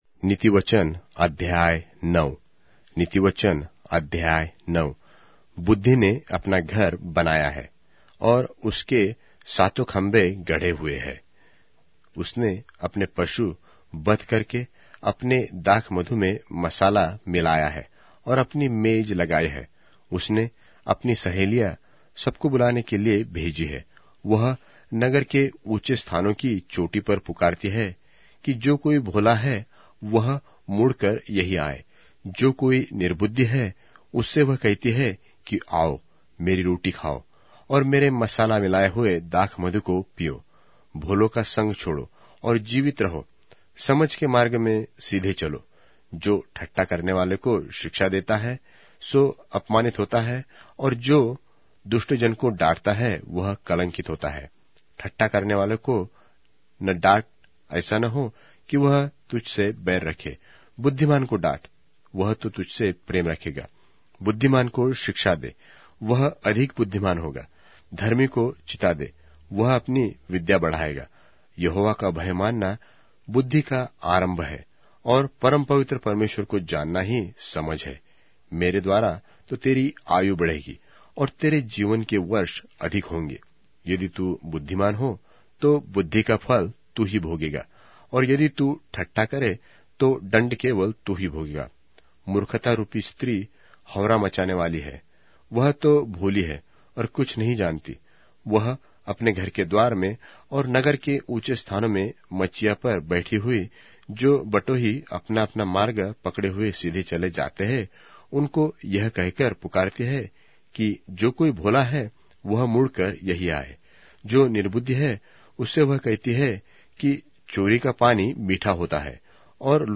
Hindi Audio Bible - Proverbs 18 in Gntbrp bible version